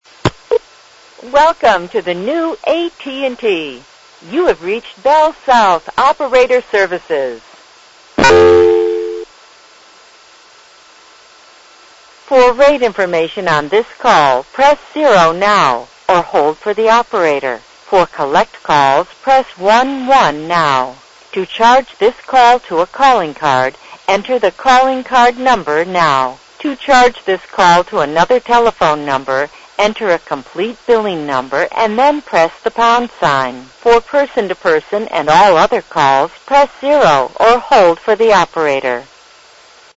The following are examples of other local or long distance telephone company operator service platforms sounds and recordings.